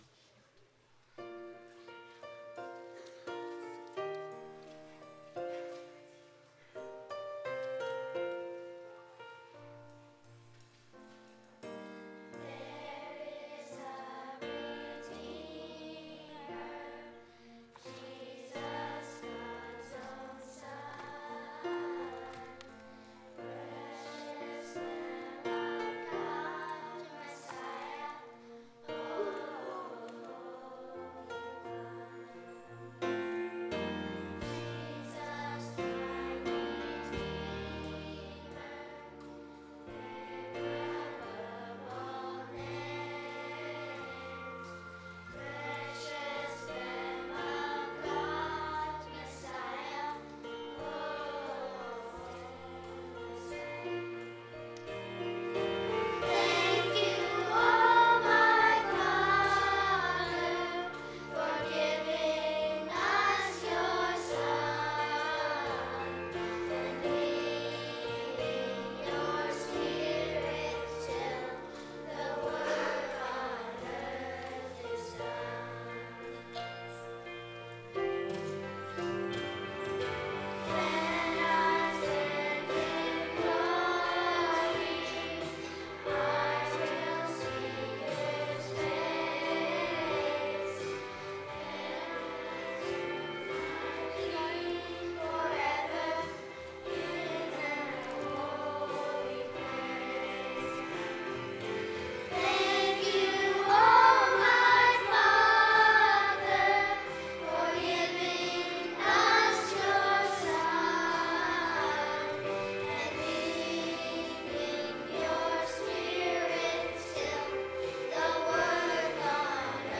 Music Forum • Jr. Choir • There is a Redeemer